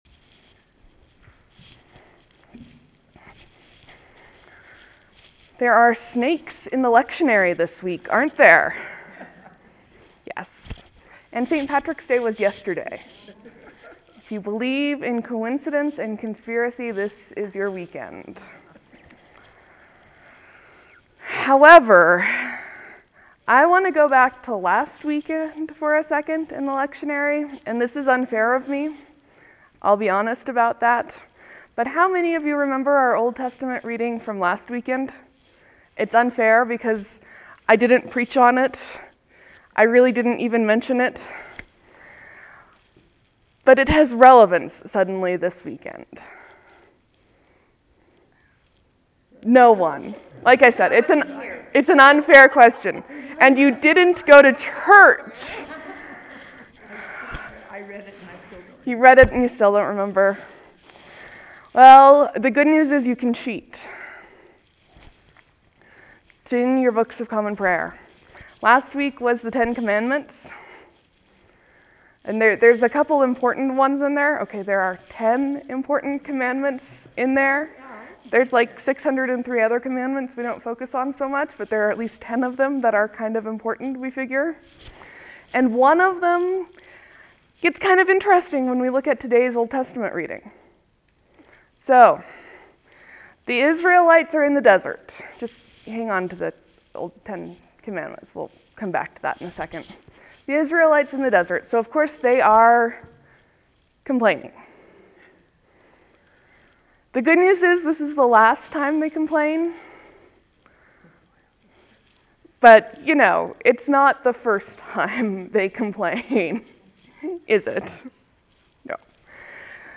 (There will be a few moments of silence before the sermon begins. This is one of my interactive sermons, while I work to make sure that the nature of the conversation is caught by the mic there may be rough patches.